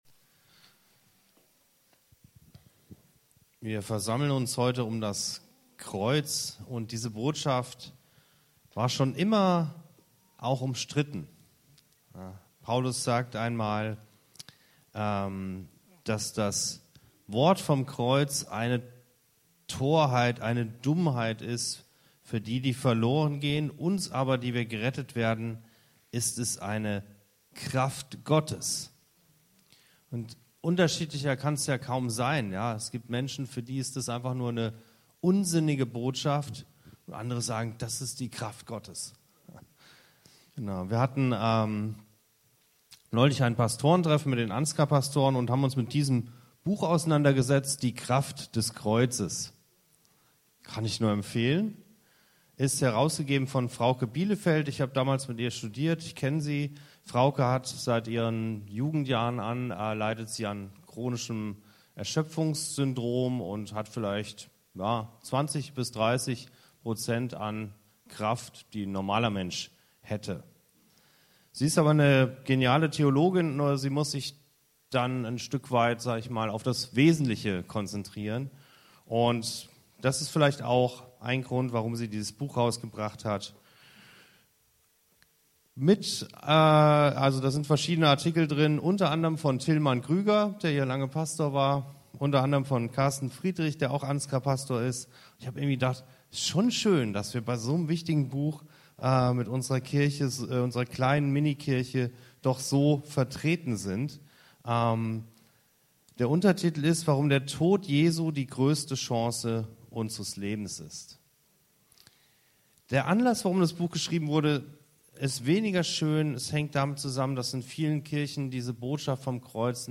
An Karfreitag erinnern wir uns an den Tod Jesu am Kreuz – doch was bedeutet das konkret für uns?